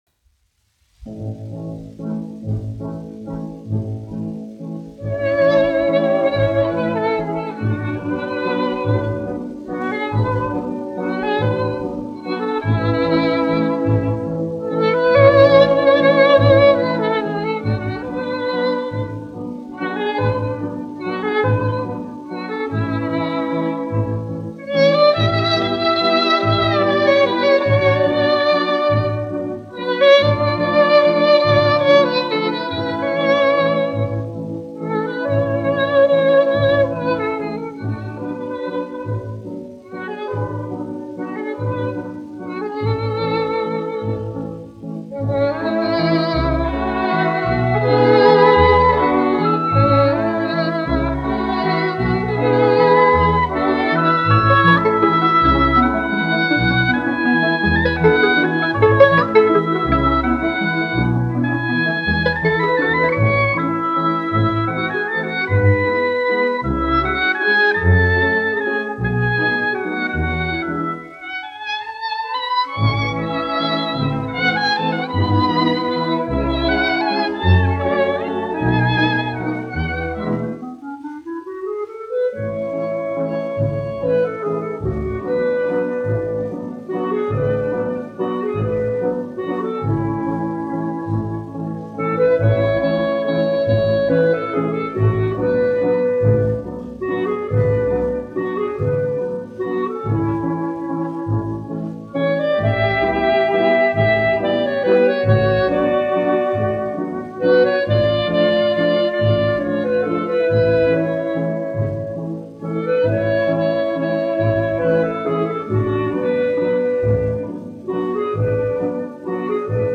1 skpl. : analogs, 78 apgr/min, mono ; 25 cm
Orķestra mūzika
Skaņuplate